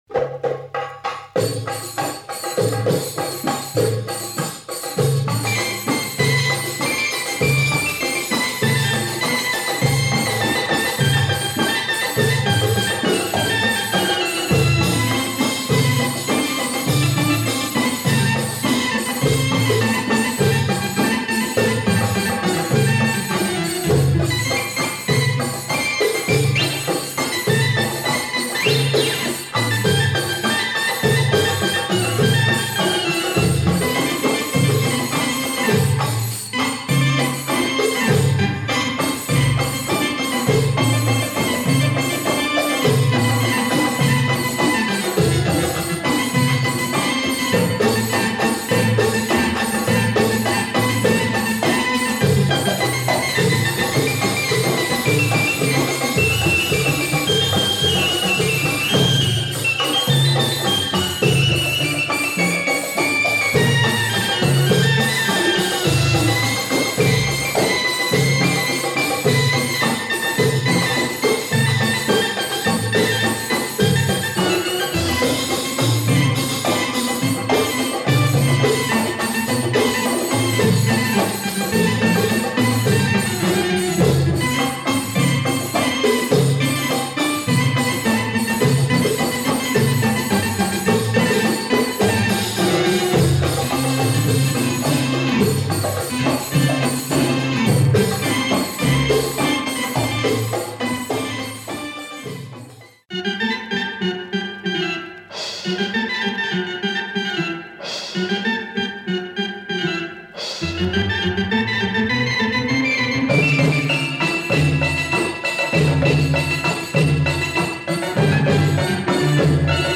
featuring some killer tunes on keyboards.